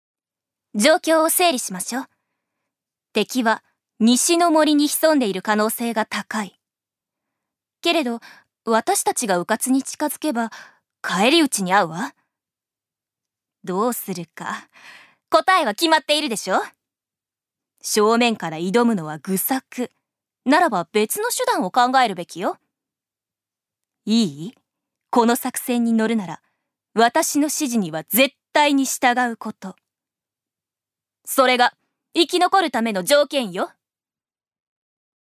預かり：女性
セリフ１